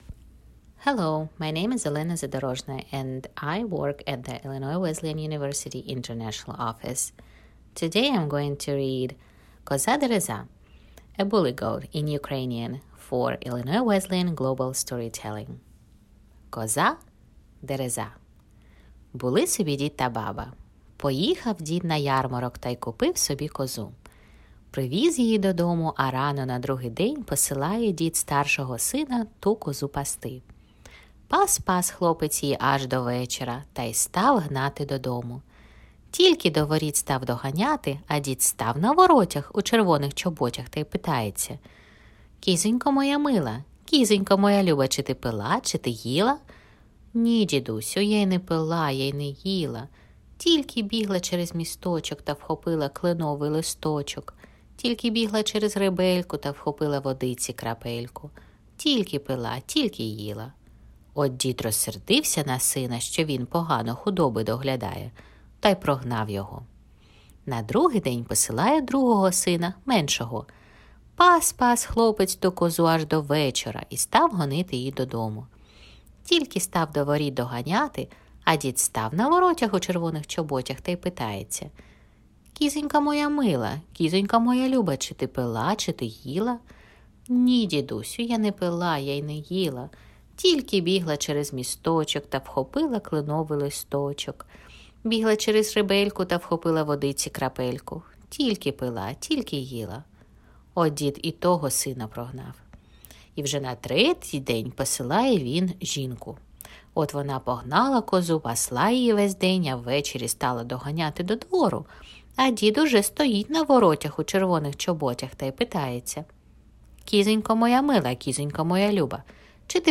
Folktale
Oral History Item Type Metadata
Kyiv, Ukraine